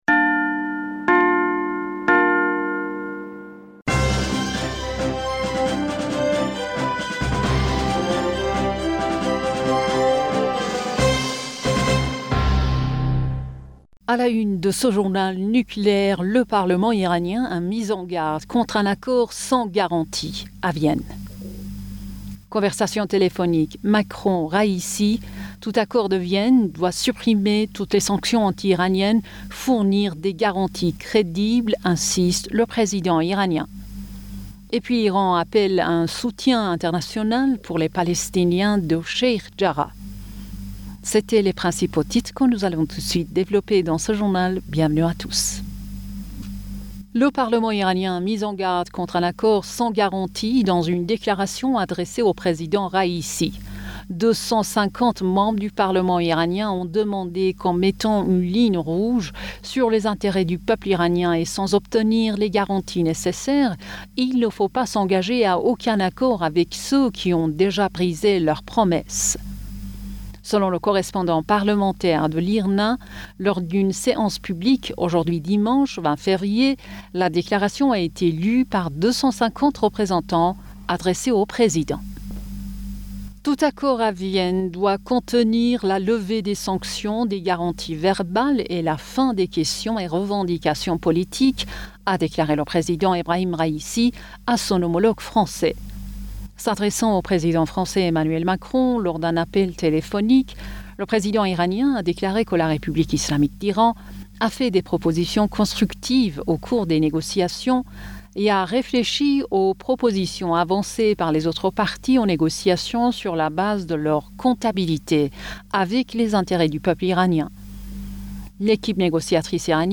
Bulletin d'information Du 20 Fevrier 2022